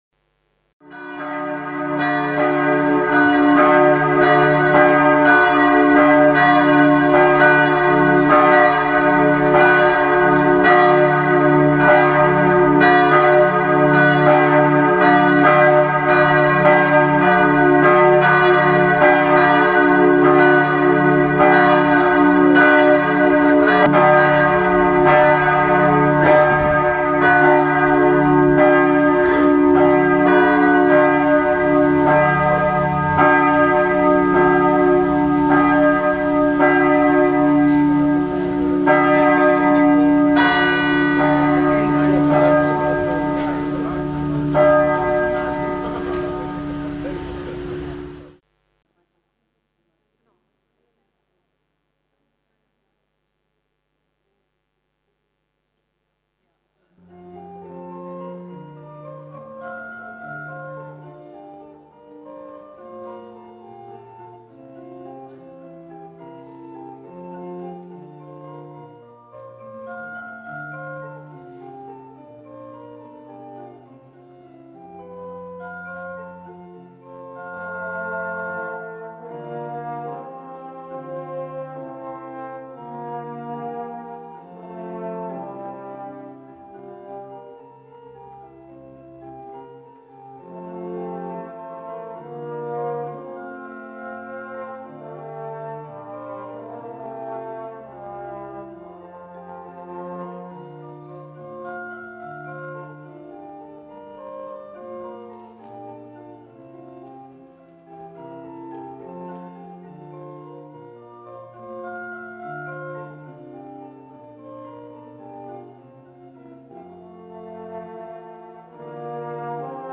Abschlussandacht zum Tag des offenen Denkmals.mp3